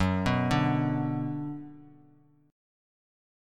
F#sus4#5 chord